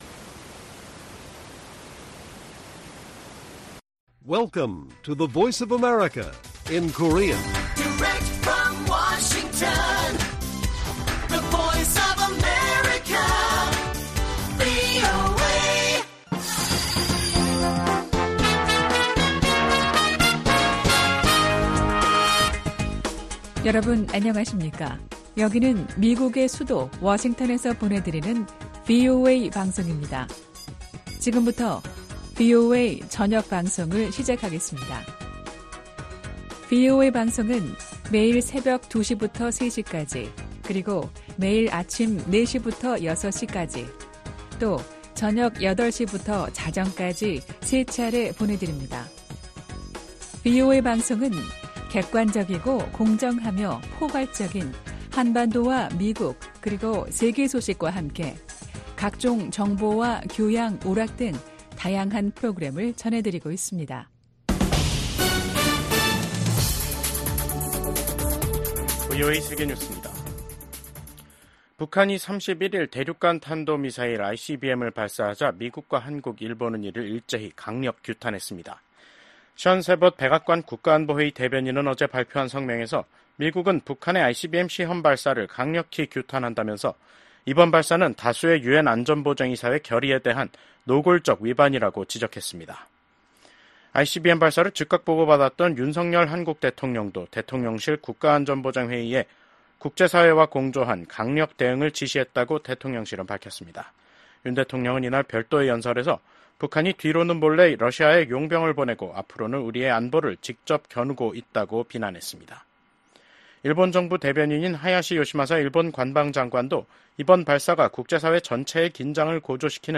VOA 한국어 간판 뉴스 프로그램 '뉴스 투데이', 2024년 10월 31일 1부 방송입니다. 북한이 미국 대선을 닷새 앞두고 동해상으로 대륙간탄도미사일(ICBM)을 발사했습니다. 미국 백악관이 북한의 대륙간탄도미사일(ICBM) 발사를 규탄하고 북한에 불안정한 행동을 중단할 것을 촉구했습니다. 미국과 한국의 국방장관이 북한군의 러시아 파병을 한 목소리로 강력하게 규탄했습니다.